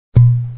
Tappo, bottiglia che si apre
Suono tipico del tappo di sughero di una bottiglia che viene aperta.
BottleCork-01.mp3